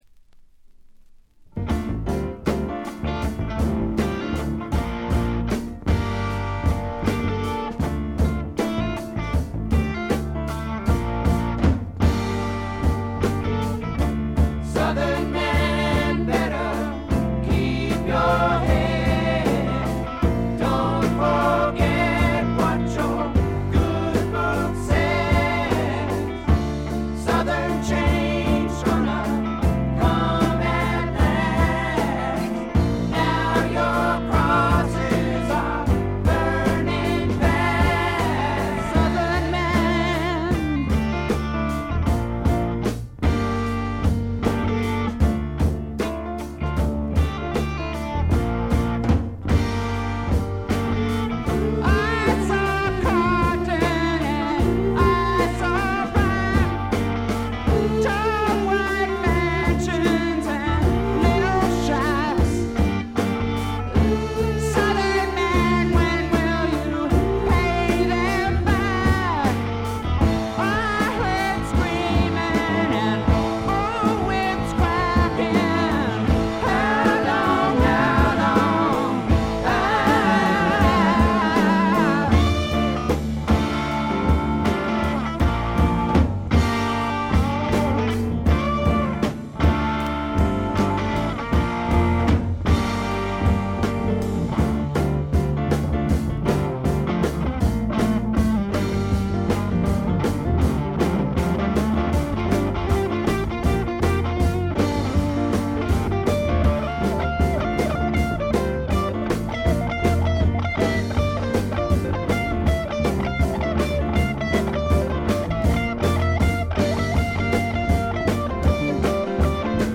試聴曲は現品からの取り込み音源です。
guitar, vocal